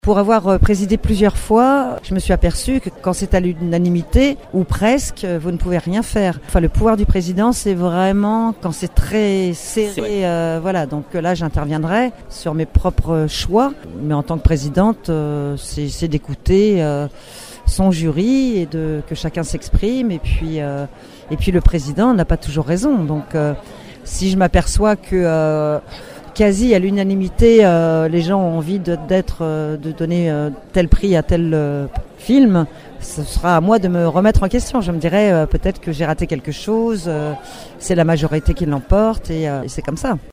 C’est un jury présidé cette année par l’actrice Sandrine Bonnaire qui sera chargé de décerner 15 prix. Et la présidente promet d’être à l’écoute de ce dernier :